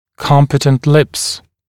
[‘kɔmpɪtənt lɪps][‘компитэнт липс]смыкающиеся губы